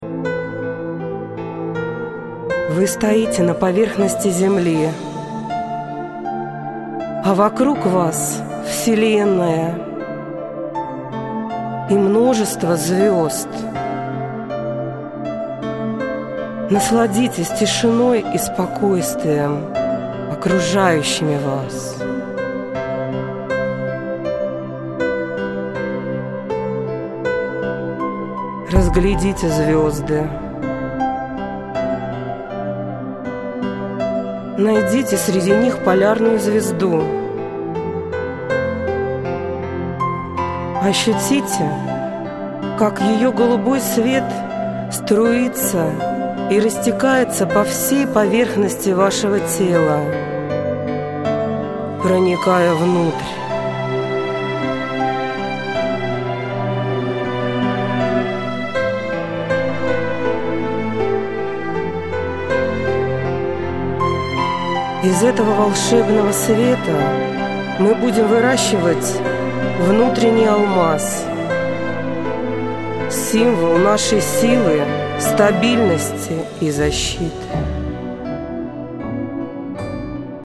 Эти медитации были созданы лично мной для ВАС и воспроизведены на студии звукозаписи.
Все медитации я зачитываю СВОИМ ГОЛОСОМ!